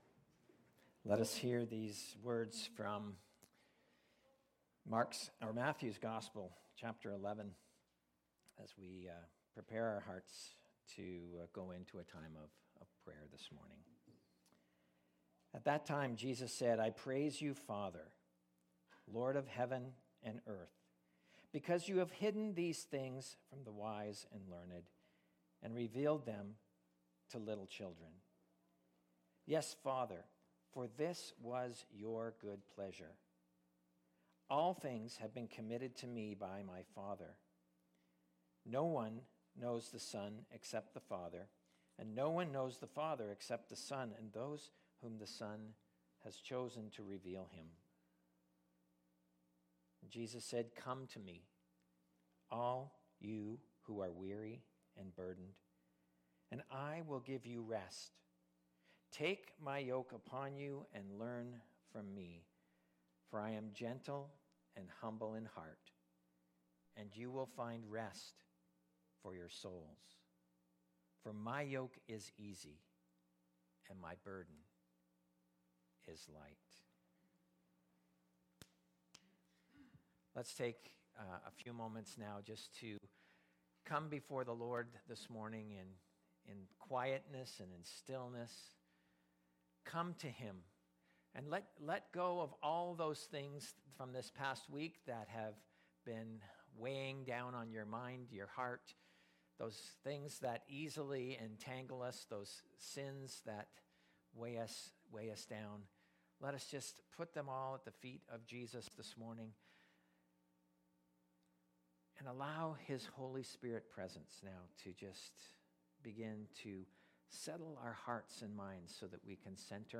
Download Download Reference Isaiah 55 Be Thou My Vision Current Sermon Are You Thirsty?